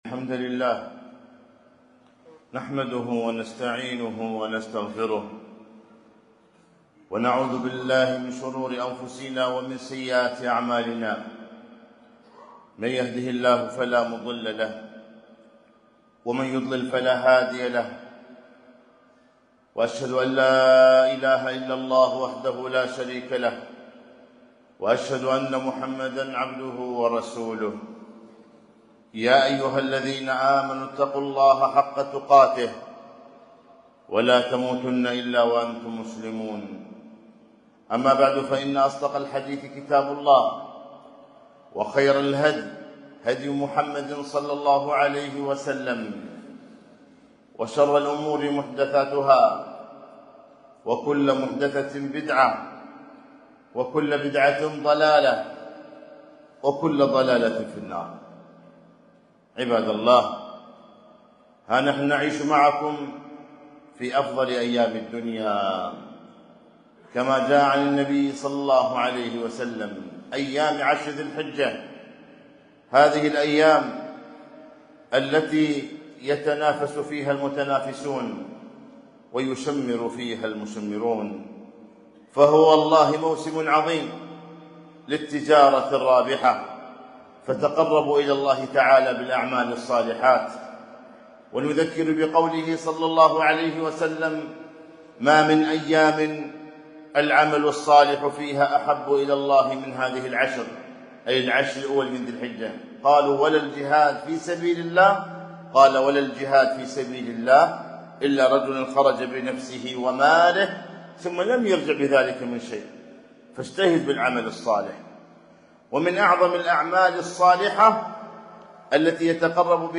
خطبة - أحكام الأضحية وآدابها